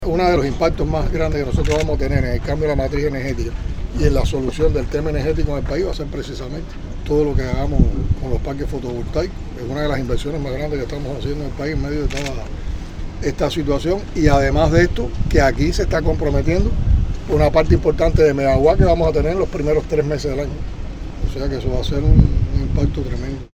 🎧 Diaz-Canel se refiere a importancia del parque solar fotovoltaico